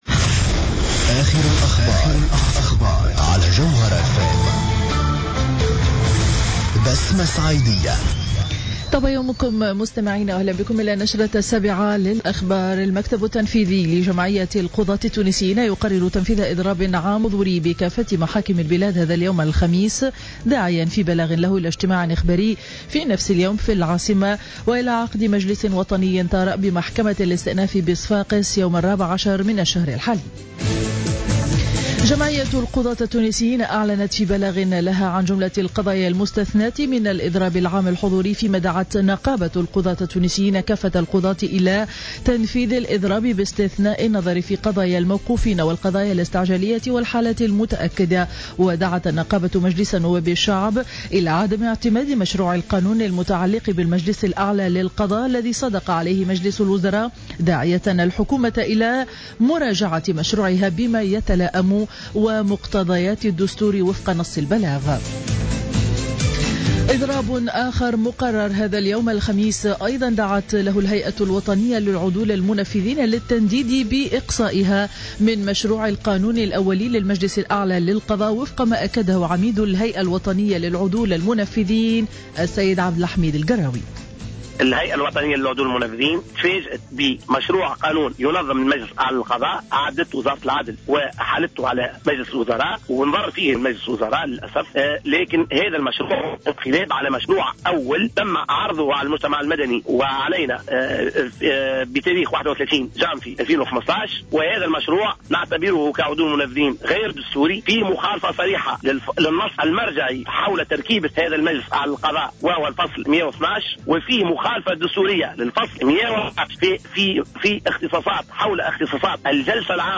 نشرة أخبار السابعة صباحا ليوم الخميس 12 مارس 2015